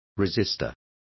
Complete with pronunciation of the translation of resistor.